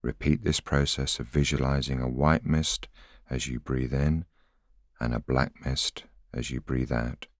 a voice sample is a single voiceover statement which can be used to compose voice collections.